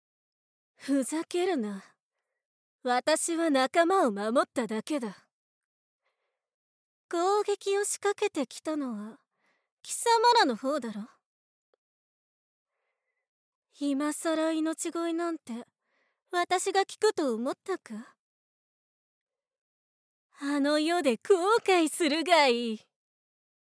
ボイスサンプル
女幹部(敵キャラ)